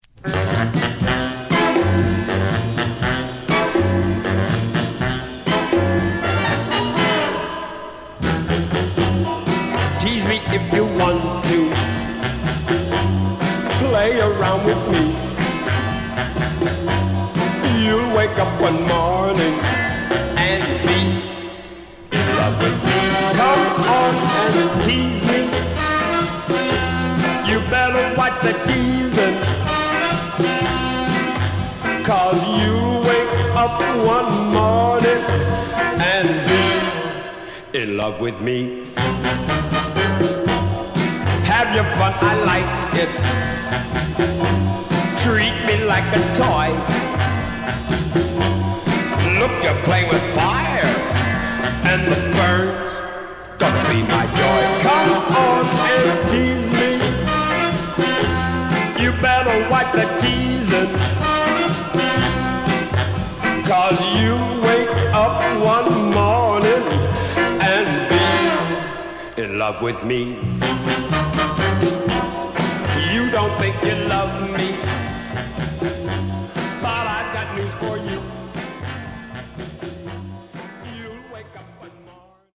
Popcorn Style Condition: M-
Stereo/mono Mono